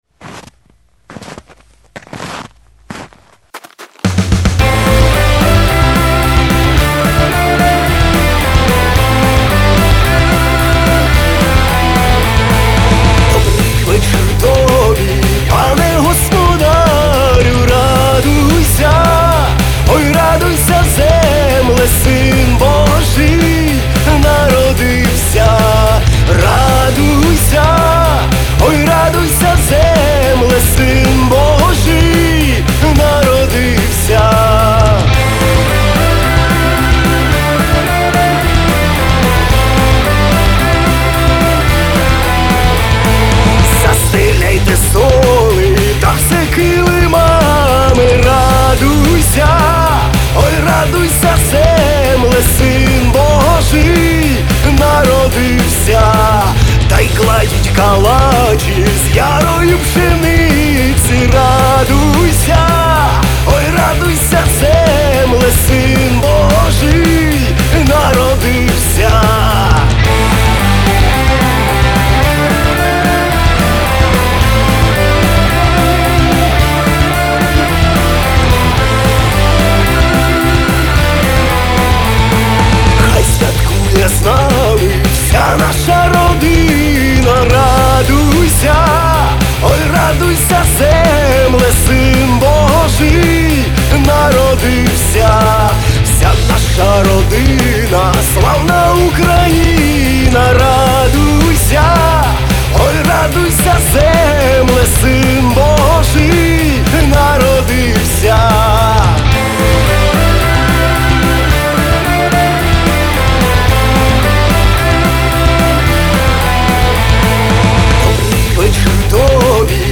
• Жанр: Rock, Folk